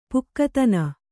♪ pukkatana